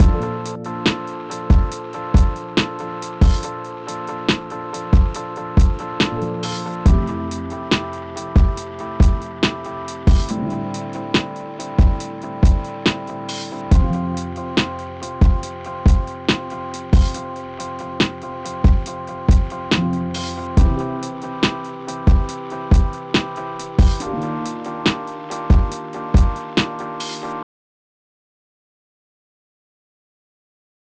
Lo-Fi Wash Over Cut 30.wav